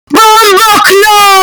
Loud Bomboclat Sound Button - Free Download & Play